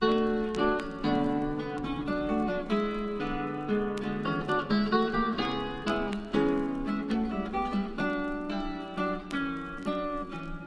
Tag: 90 bpm LoFi Loops Guitar Acoustic Loops 1.79 MB wav Key : Dm FL Studio